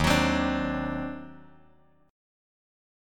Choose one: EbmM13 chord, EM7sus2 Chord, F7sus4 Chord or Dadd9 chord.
EbmM13 chord